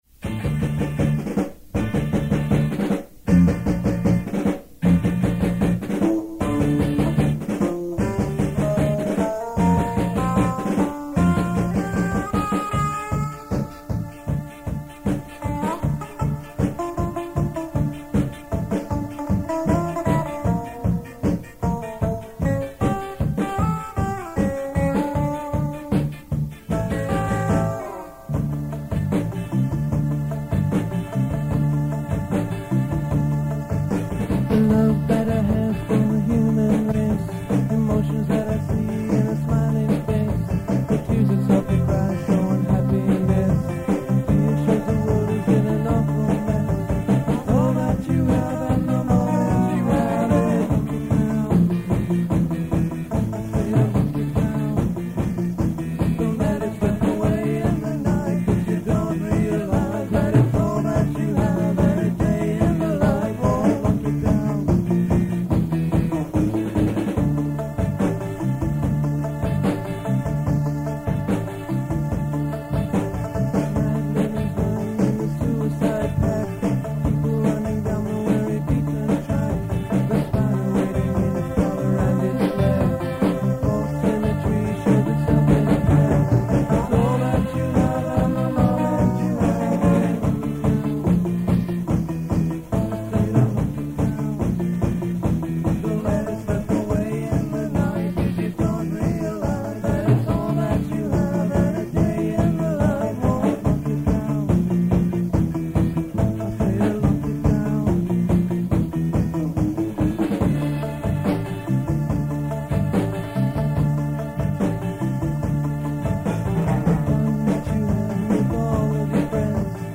Keep in mind that these are from very old cassette tapes, so sound quailty is definitely an issue.
written by Silent Q: from the Rehearsal tape
This song was a slight departure from the usual ska style.